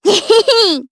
Miruru-Vox-Laugh_jp.wav